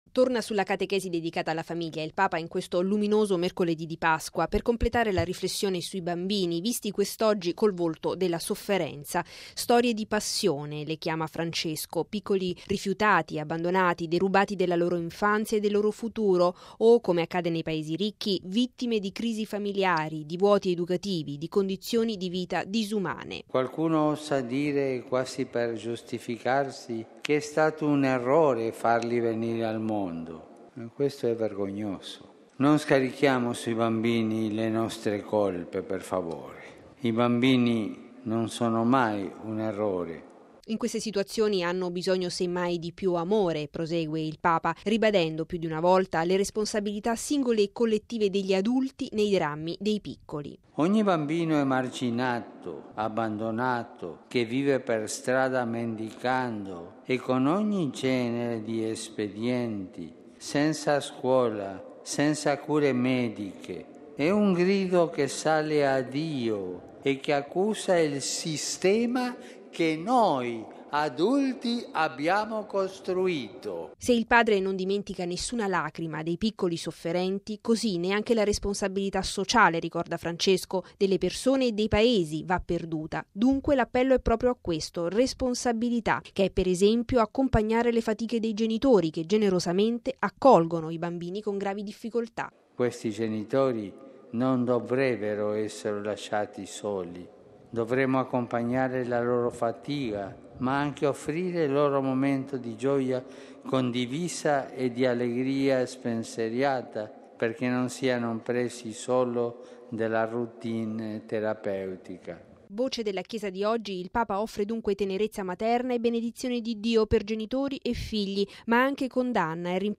E’ un forte appello alla responsabilità delle persone e dei Paesi nei confronti dei piccoli sofferenti, il filo conduttore della catechesi del Papa oggi all’udienza generale. Davanti a migliaia di fedeli in una Piazza San Pietro assolata, Francesco ripete “con i bambini non si scherza! Nessun sacrificio sarà troppo grande pur di evitare di farli sentire uno sbaglio”.